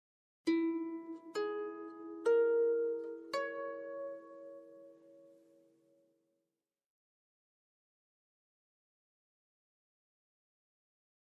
Harp, Very Slow Reverberant Arpeggio, Type 3